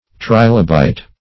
Trilobite \Tri"lo*bite\ (tr[imac]"l[-o]*b[imac]t), n. [Cf. F.